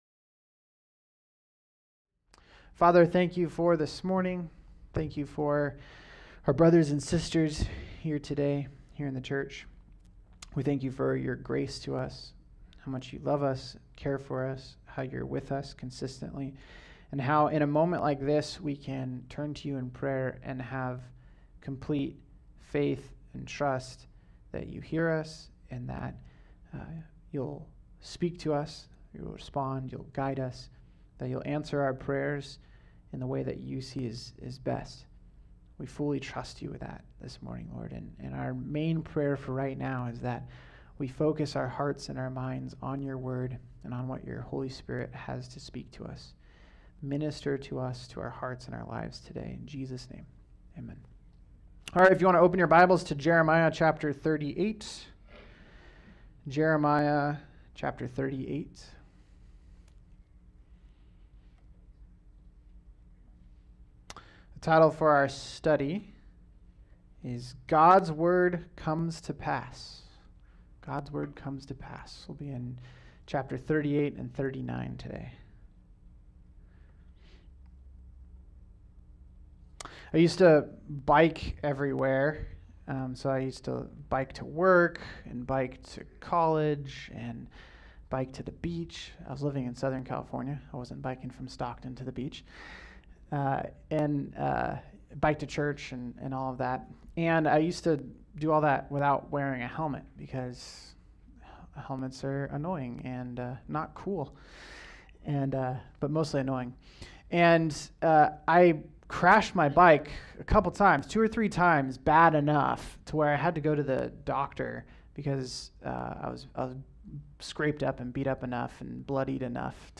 All Sermons
Topic: Sunday Morning 2024